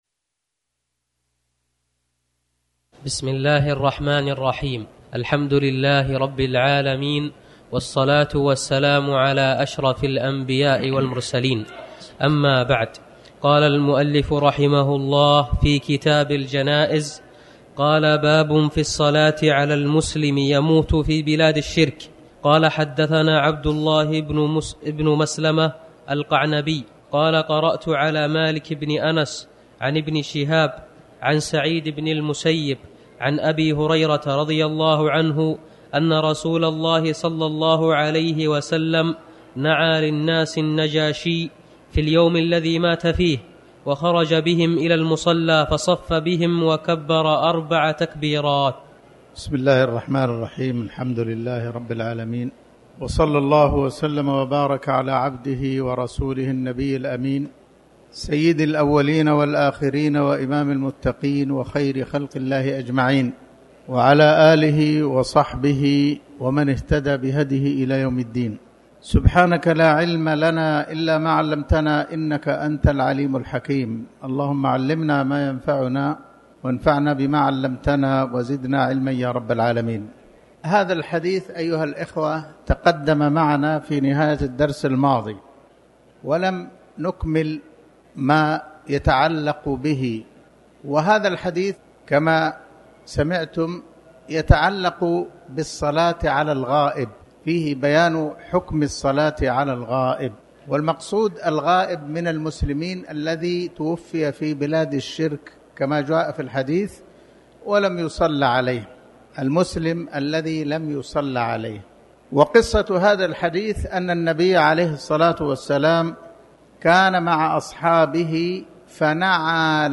تاريخ النشر ٩ ربيع الأول ١٤٤٠ هـ المكان: المسجد الحرام الشيخ